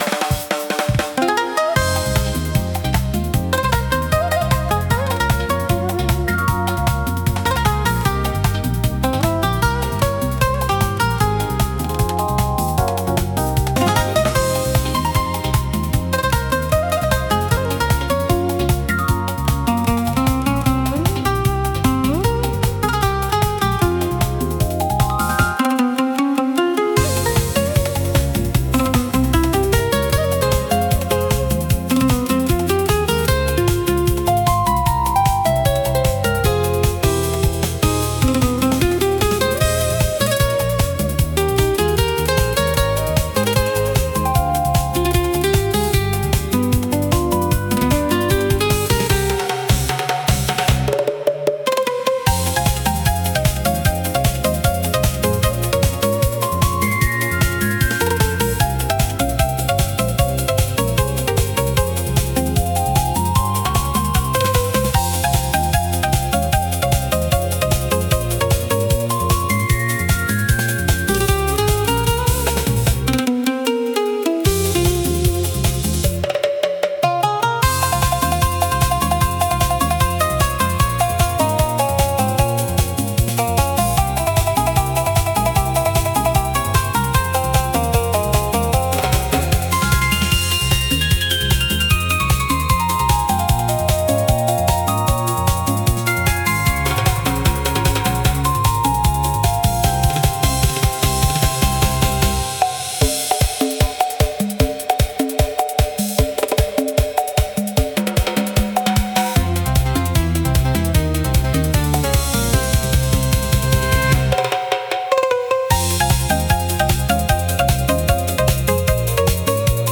イメージ：インスト,日本
インストゥルメンタル（instrumental）